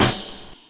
Snare (Ghost Town-2).wav